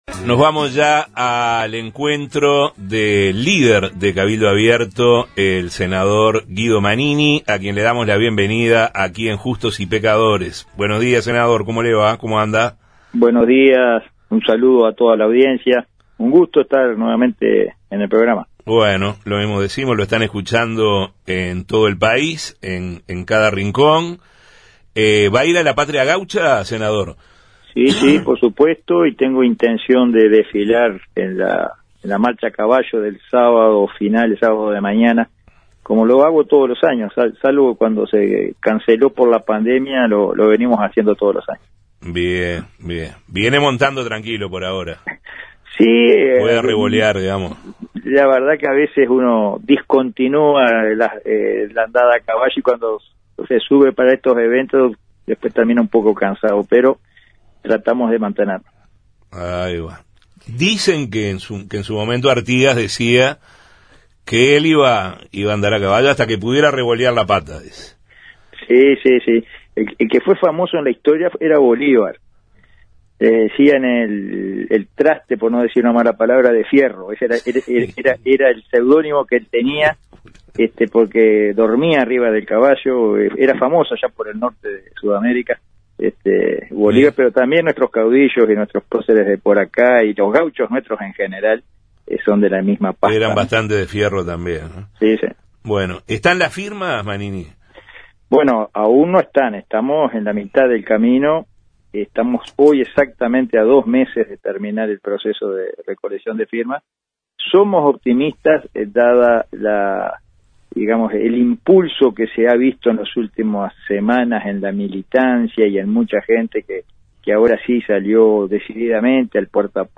ENTREVISTA-MANINI-RIOS.mp3